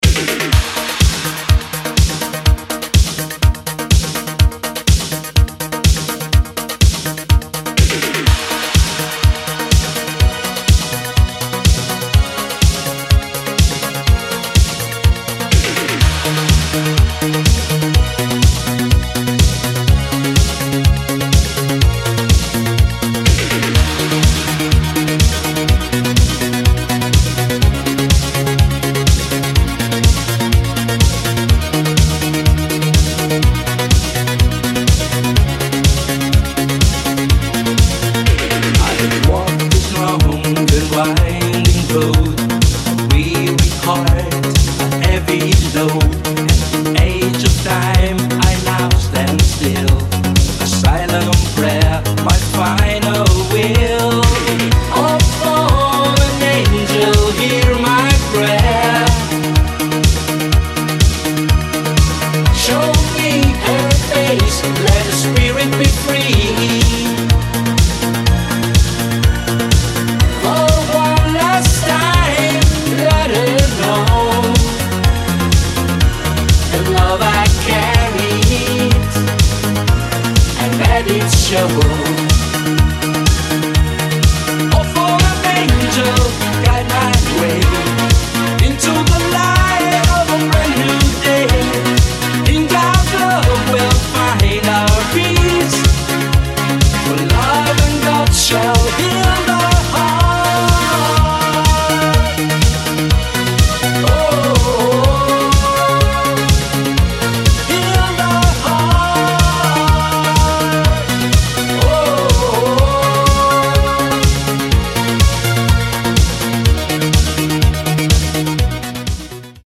An uptempo energetic Italo Disco track